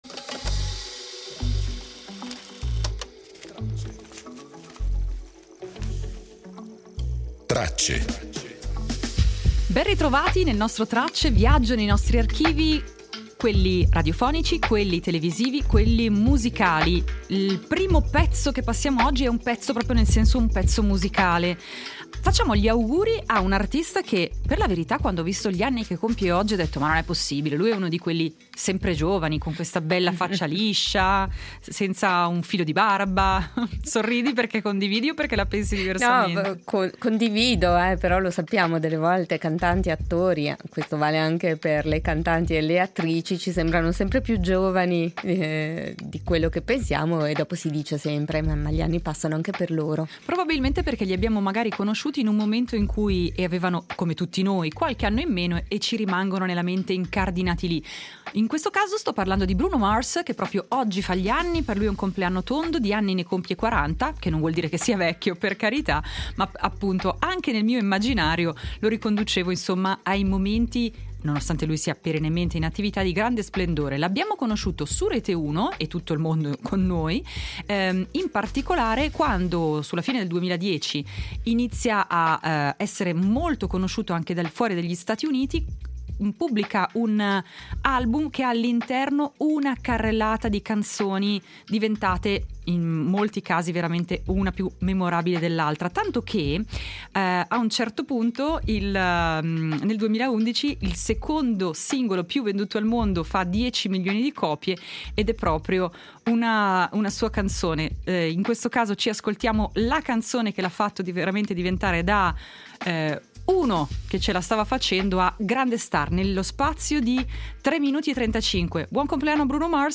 Estratti d’archivio sui marunatt e le castagne, sulla figura del parrucchiere-artista alla fine deli anni Sessanta e sulle mitiche figurine Panini.